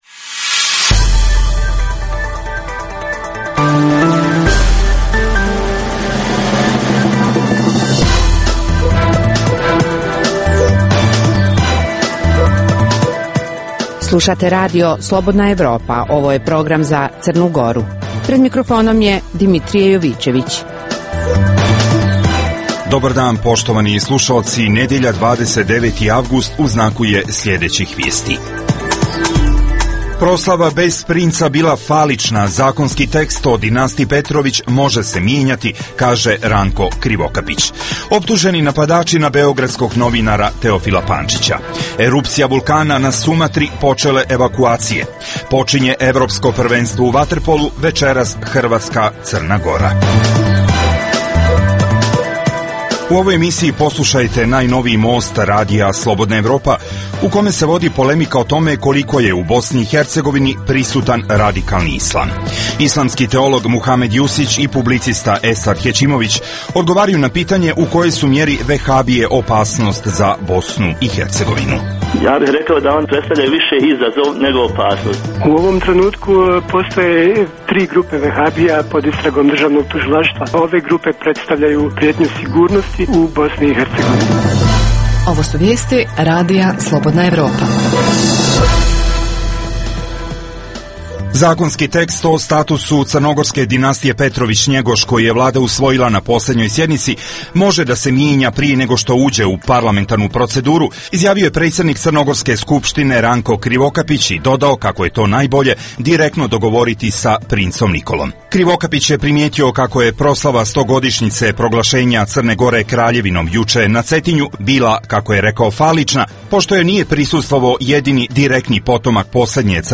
Emisija namijenjena slušaocima u Crnoj Gori. Sadrži lokalne, regionalne i vijesti iz svijeta, rezime sedmice, intervju "Crna Gora i region", tematske priloge o aktuelnim dešavanjima u Crnoj Gori i temu iz regiona.